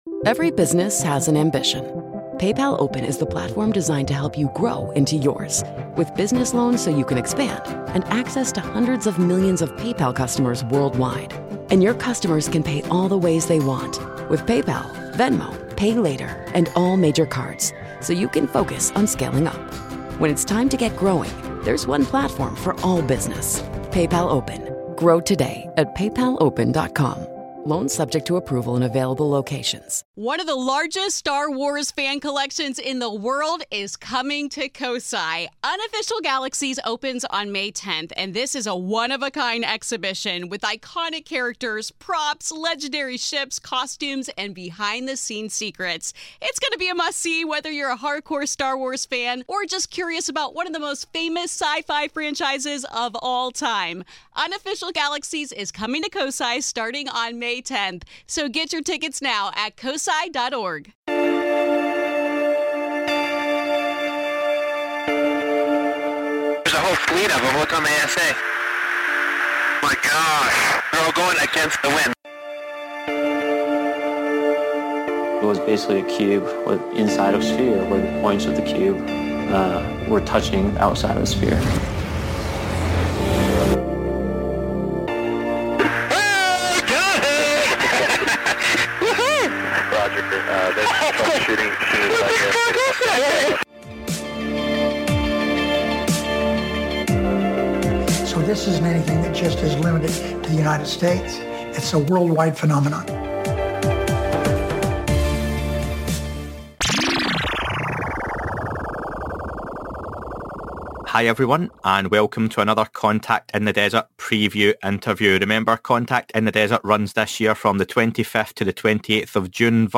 Another CITD preview is here as Ralph Blumenthal, Pulitzer prize winning journalist of the New York Times and other organistations joins me to discuss his new book on the life of John Mack & more....